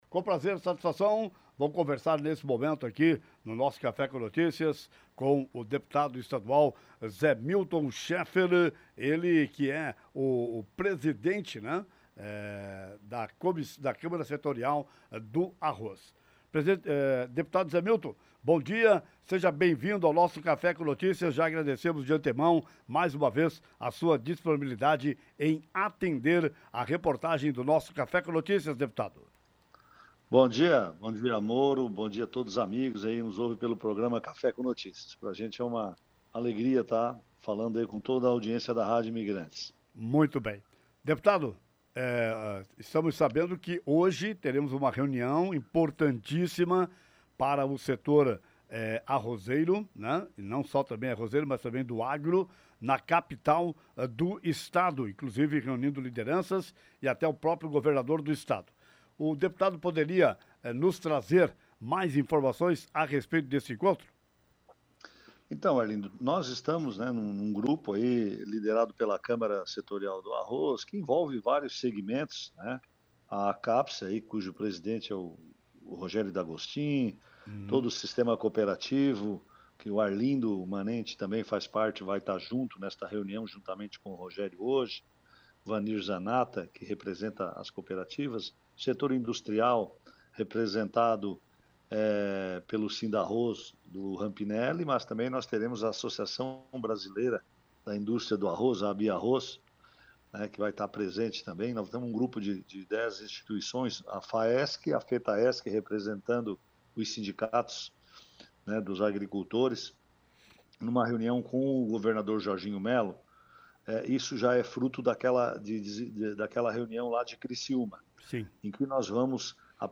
Representantes do setor do agro se reúnem com o governador Jorginho Mello nesta quarta-feira (14/01) na capital do estado: Deputado Zé Milton (PP) organizador do evento falou nesta manhã no programa Café com Notícias sobre o objetivo do encontro. Ouça a entrevista completa aqui: